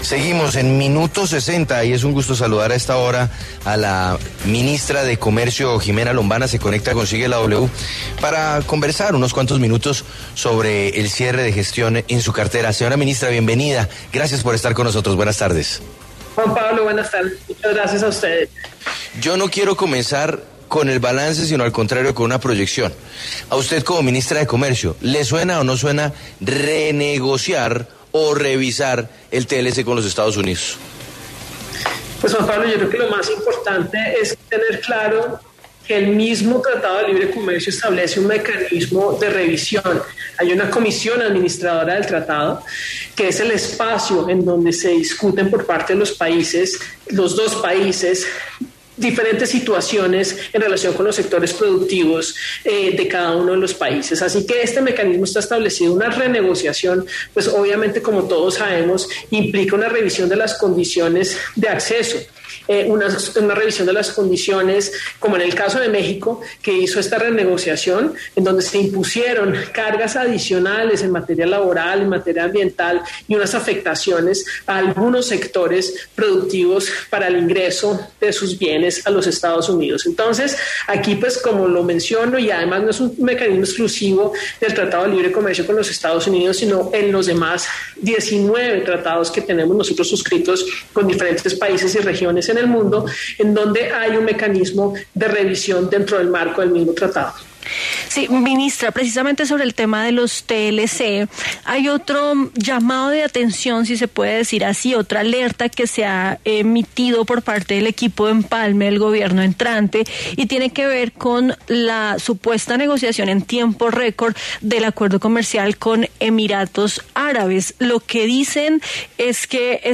Ximena Lombana, ministra de Comercio, Industria y Turismo, conversó en Sigue La W sobre el balance de su gestión de cara a un nuevo gobierno.